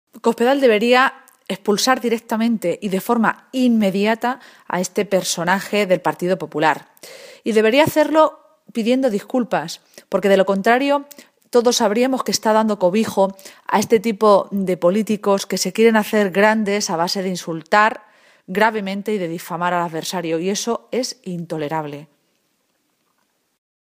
Cristina Maestre, portavoz del PSOE de C-LM
Cortes de audio de la rueda de prensa